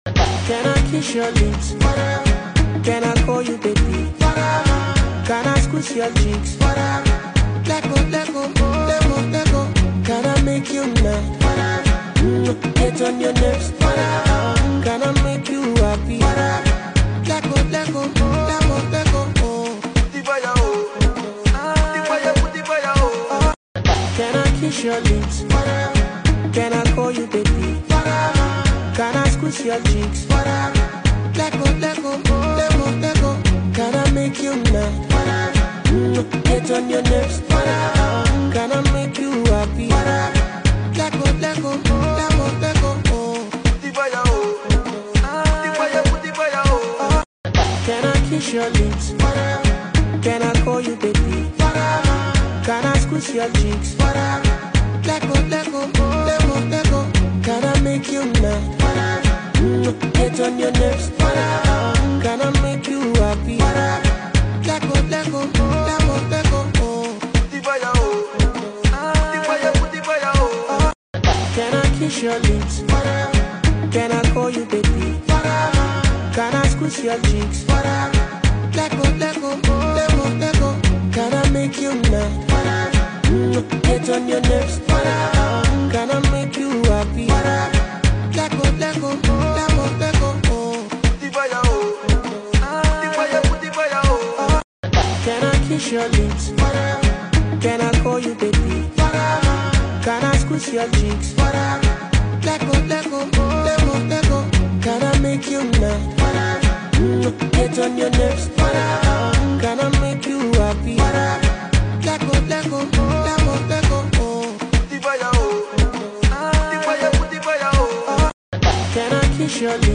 electrifying single